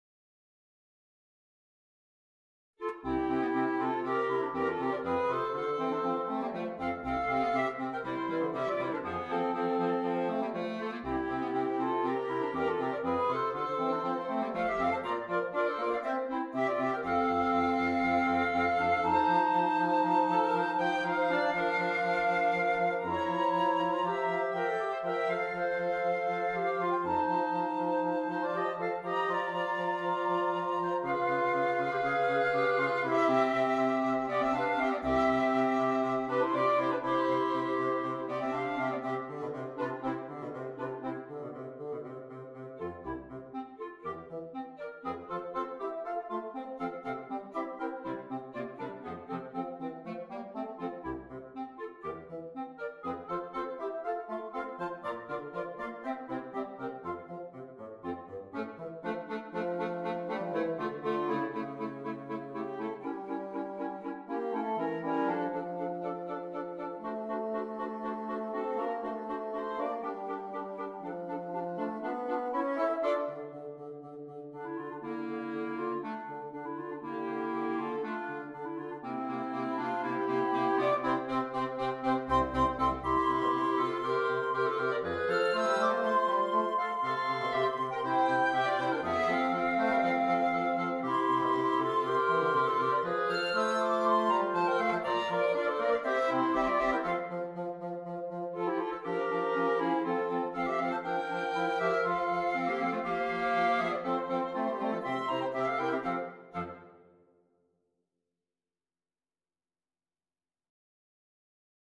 Flute, 2 Clarinet, Bassoon